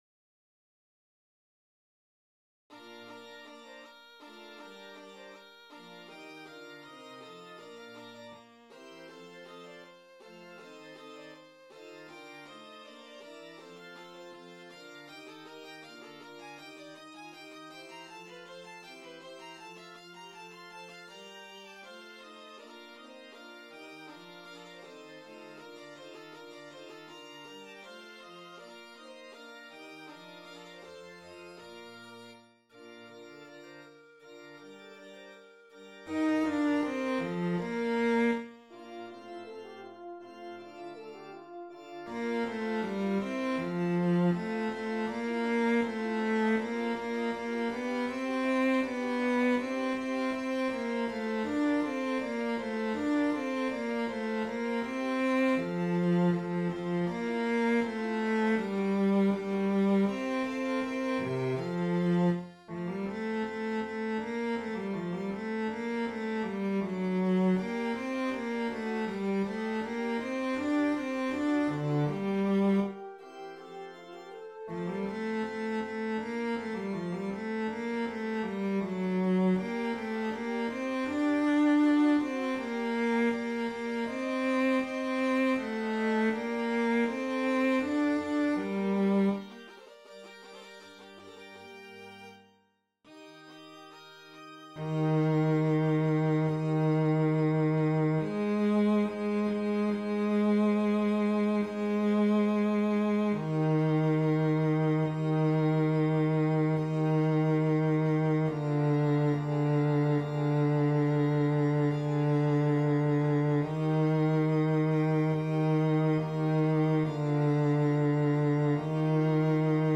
Stimmen - MIDI / mp3 (Chor) mp3